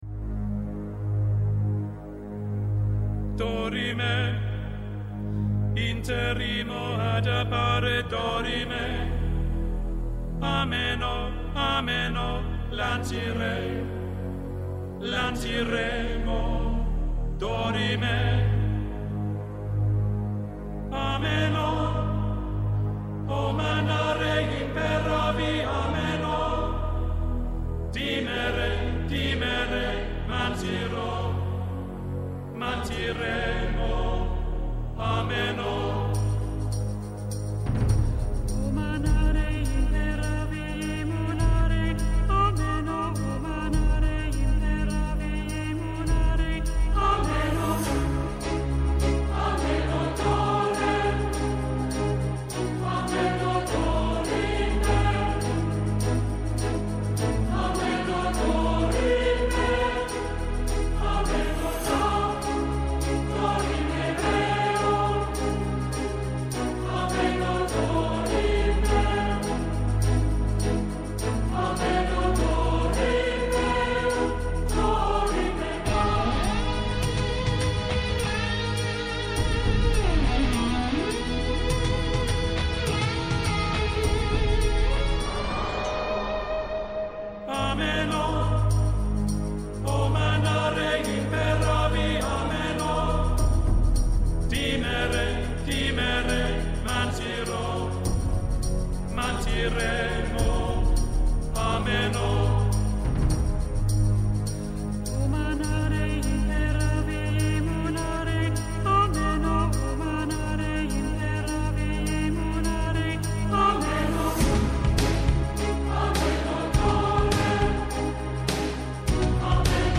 -Ο Αλέξης Βαφεάδης, υπουργός Μεταφορών, Επικοινωνιών και Έργων της Κύπρου
-Η Χριστίνα Αλεξοπούλου, υφυπουργός Μεταφορών και Υποδομών